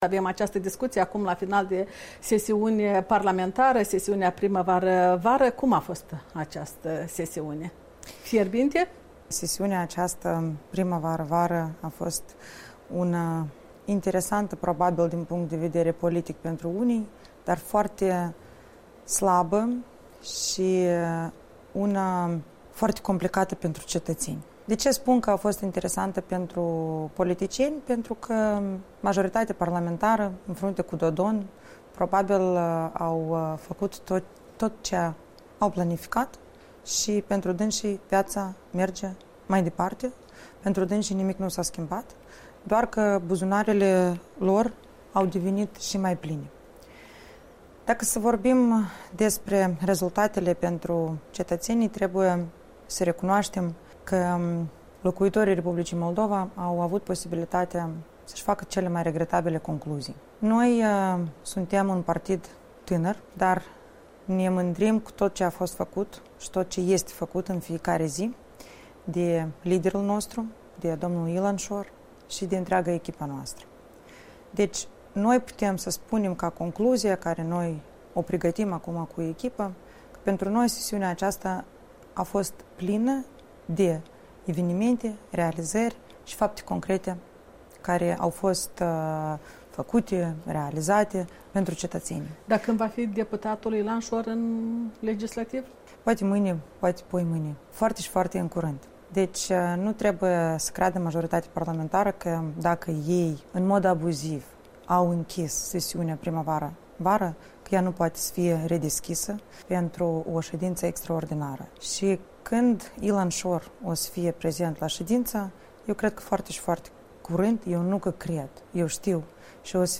Interviu cu Marina Tauber